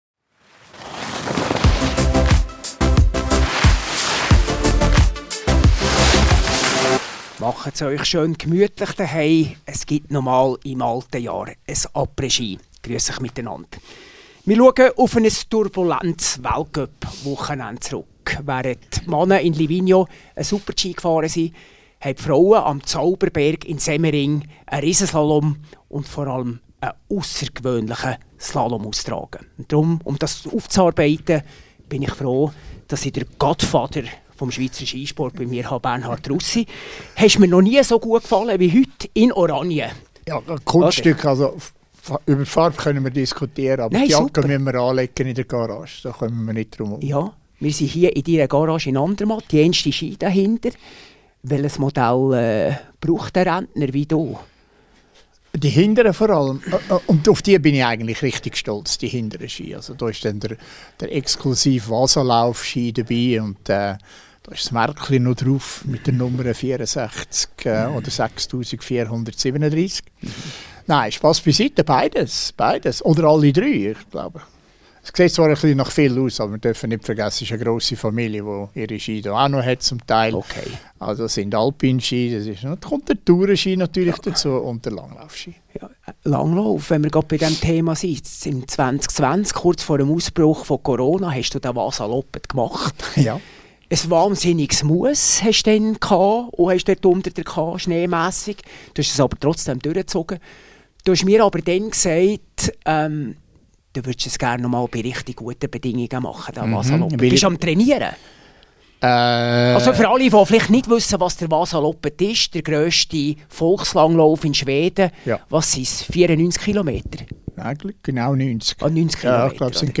Und Russi spielt noch ein Ständchen.